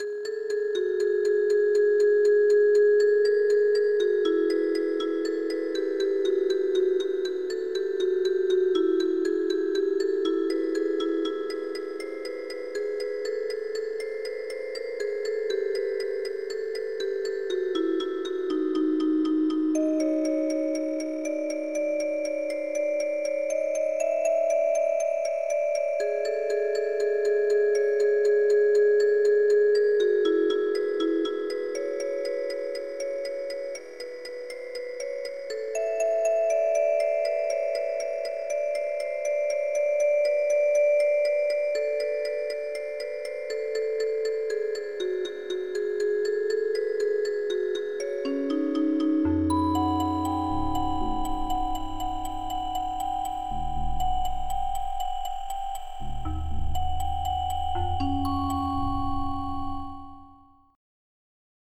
Creating Baroque Music with a Recurrent Neural Network
Training a recurrent neural network to generate baroque music